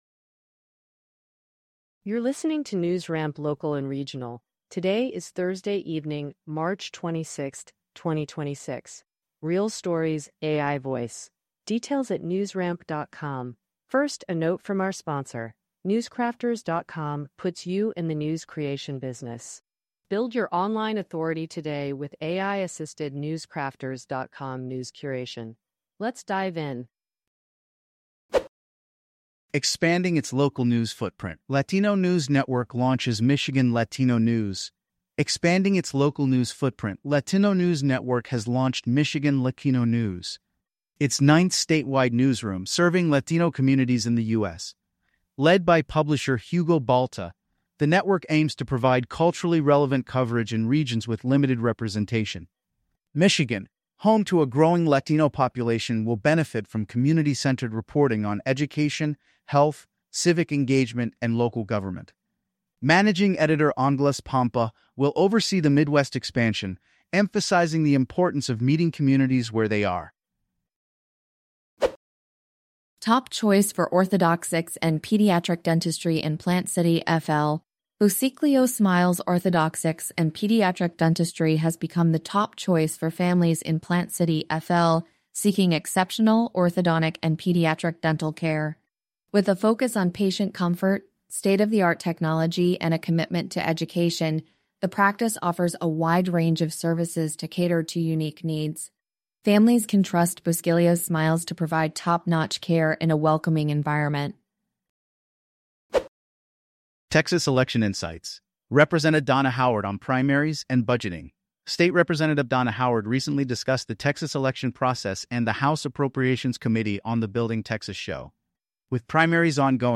NewsRamp Local and Regional News Podcast